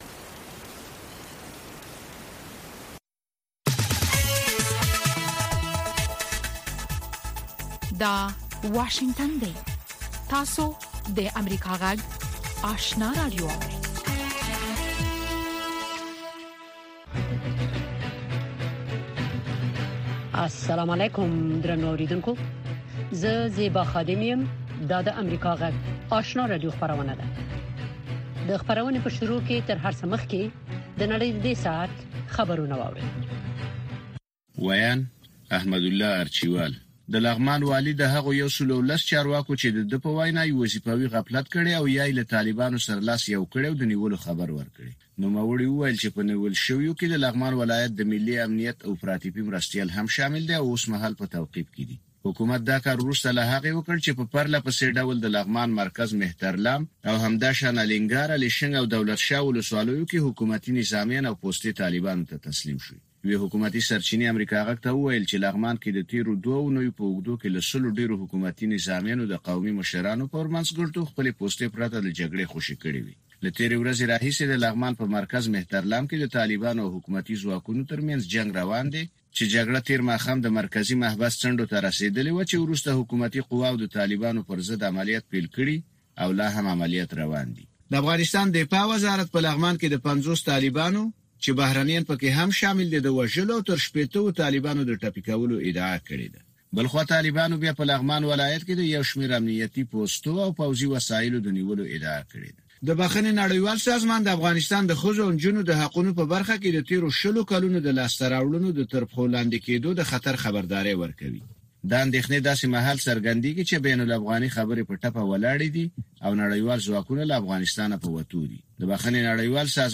لومړۍ ماښامنۍ خبري خپرونه